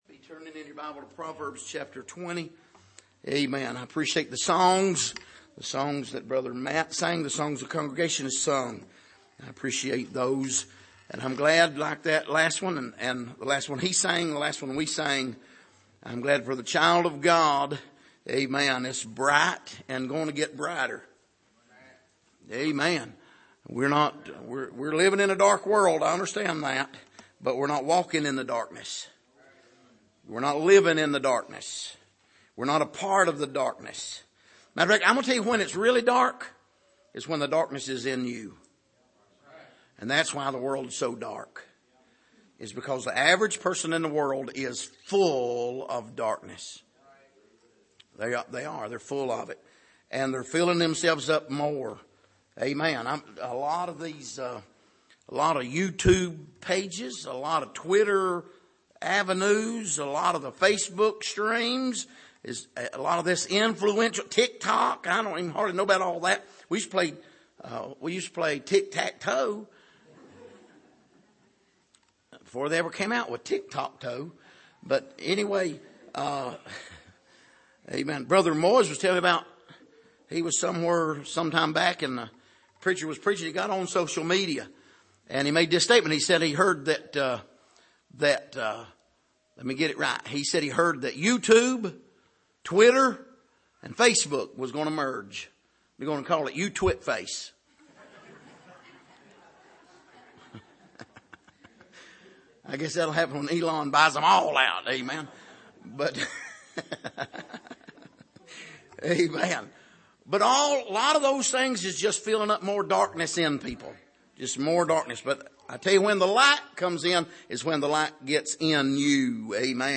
Passage: Proverbs 20:16-22 Service: Sunday Evening